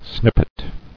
[snip·pet]